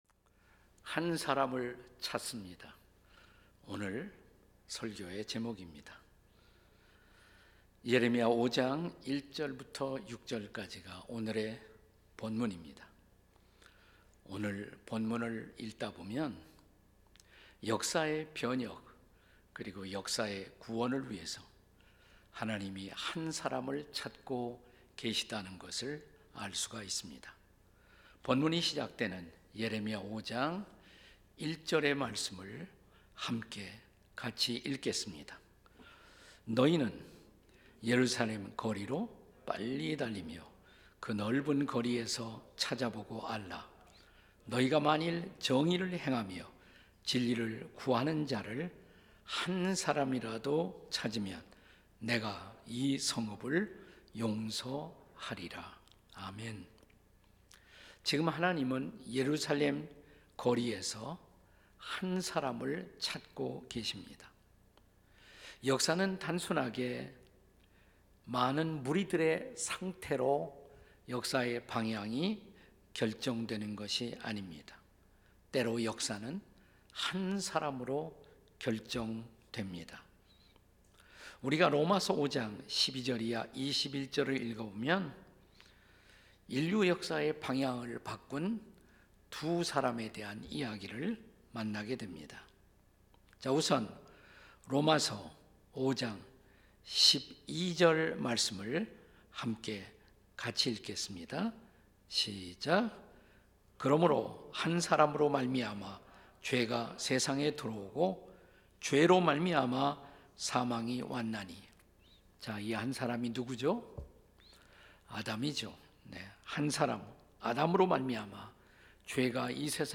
설교 : 주일예배